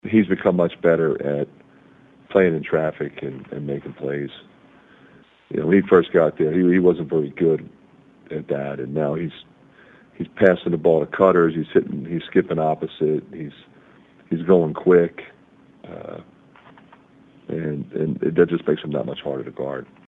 McCaffery says Edey is not only one of the top players in the nation he is also one of the most improved.